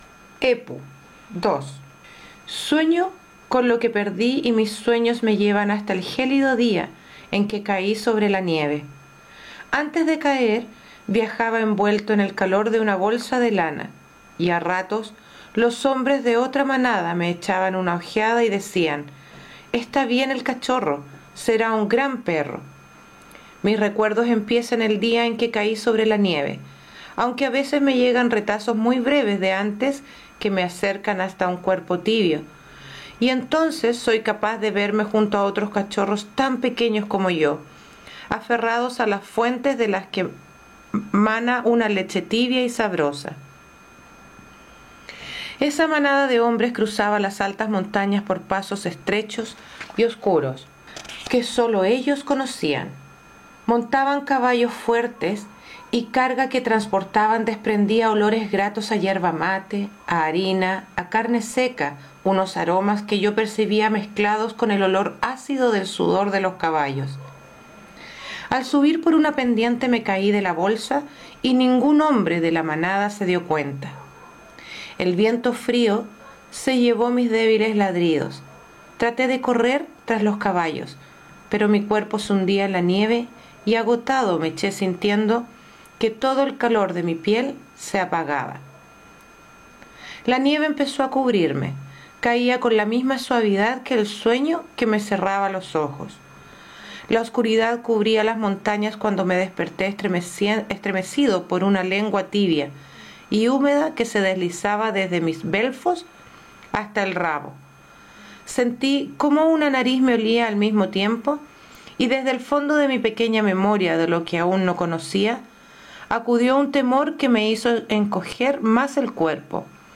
Lectura muzicalizada: "Historia de un perro llamado Leal"